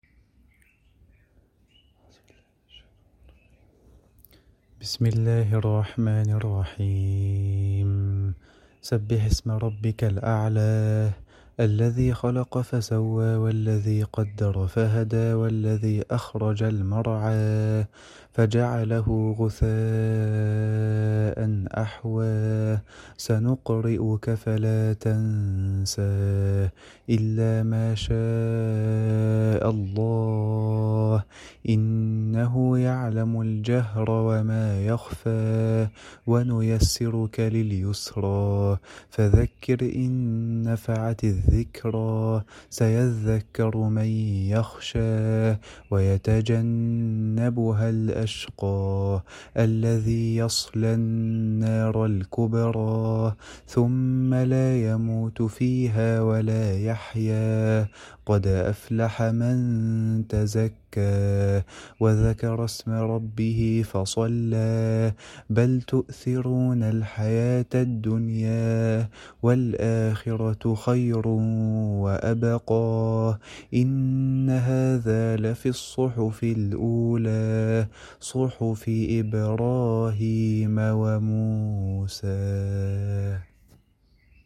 Qutor_recitation.mp3